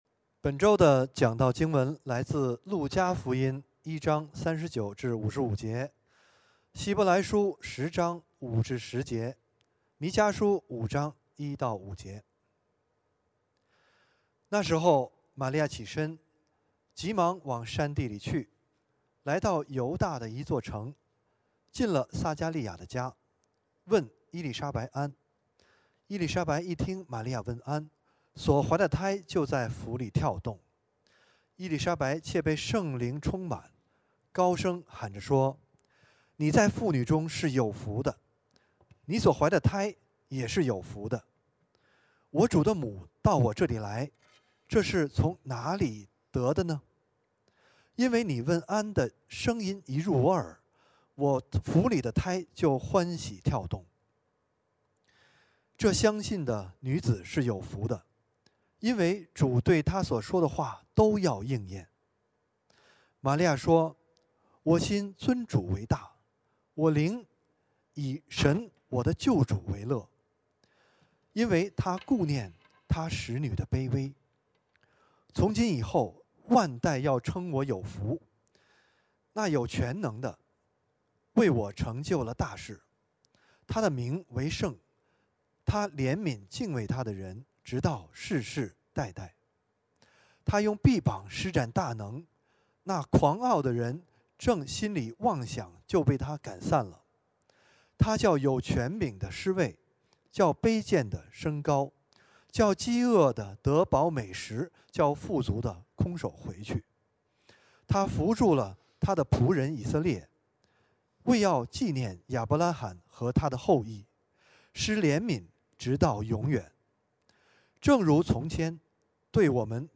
主日证道｜平安就将临 – BICF – Beijing International Christian Fellowship